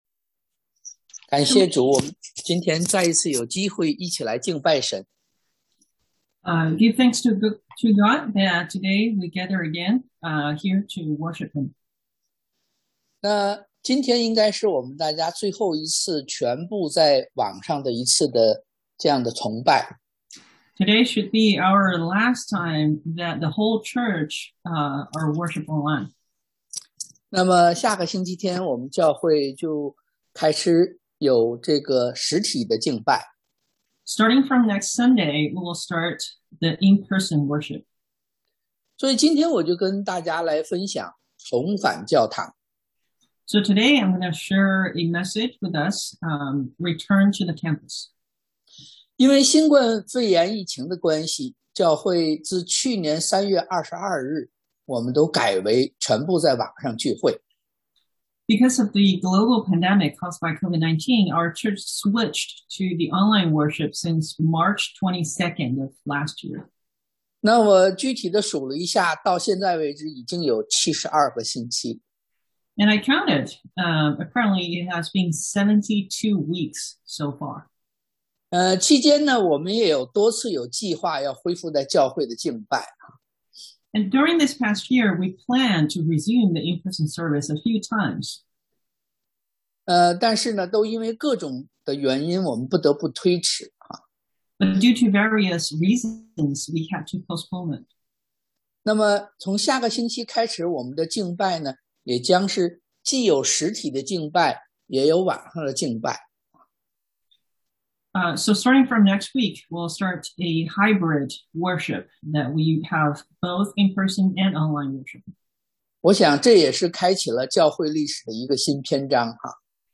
尼 Neh 2:8 Service Type: Sunday AM Return to the Campus 重返教堂 Passage 經文：尼Neh 2:8 1.